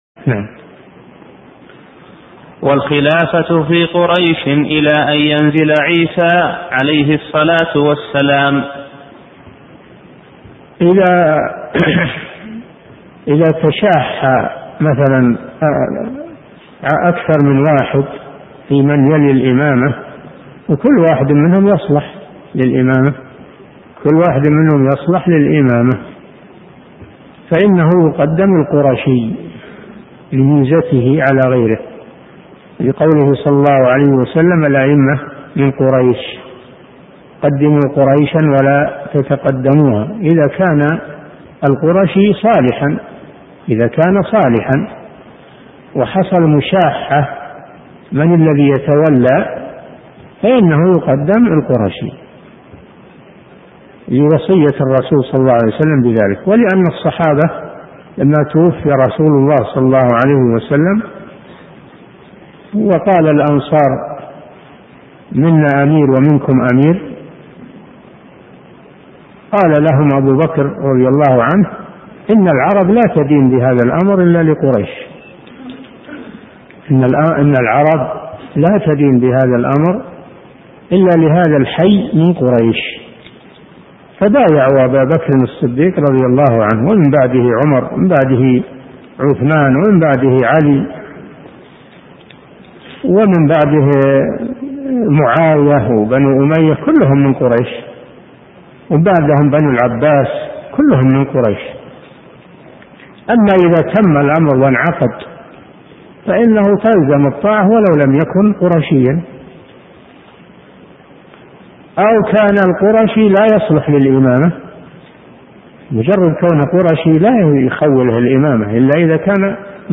Download audio file Downloaded: 232 Played: 791 Artist: الشيخ صالح الفوزان Title: شرح قول البربهاري : ومن خرج على إمام من أئمة المسلمين فهو خارجي قد شق عصا المسلمين Length: 11:01 minutes (1.29 MB) Format: MP3 Mono 16kHz 16Kbps (CBR)